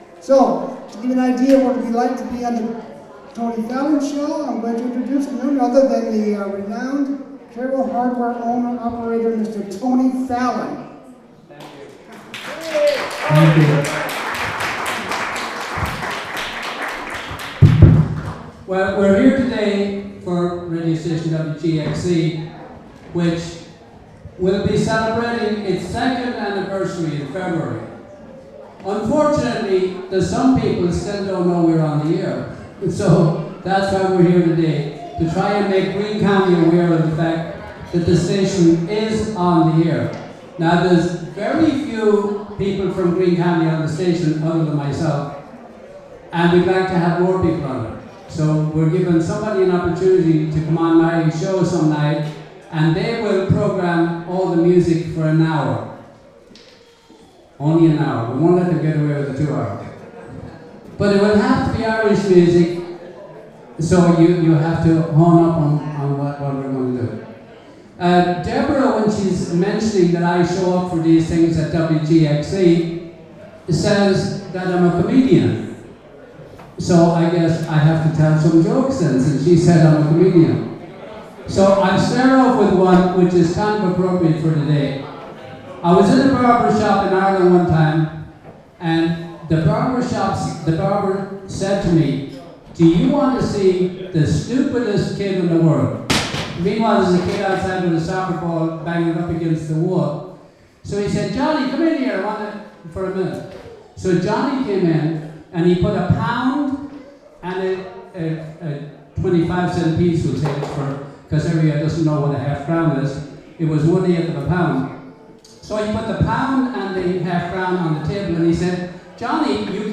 WGXC 90.7-FM Meet & Greet: Nov 18, 2012: 2pm - 5pm